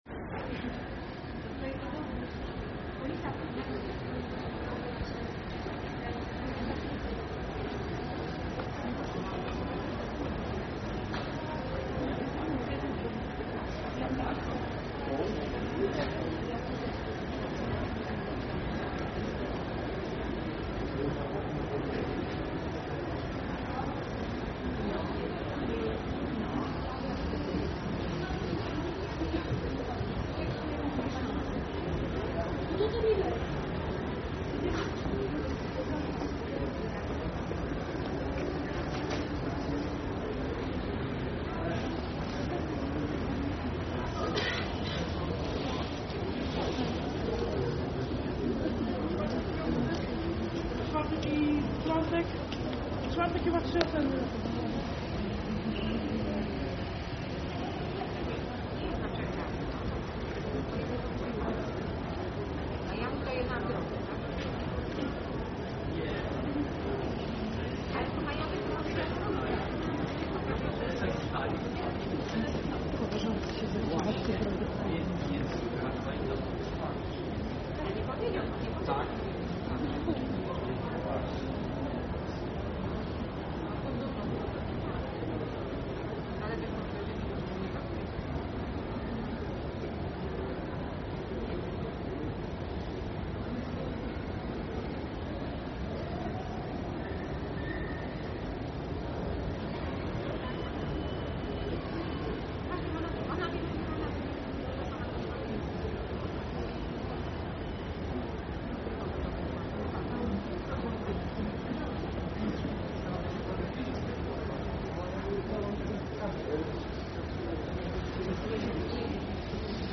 Nagranie nr 12, Wroclavia, Wejście Główne, 13 stycznia 2025, godz. 18:20, czas trwania: 2 min. 53 s.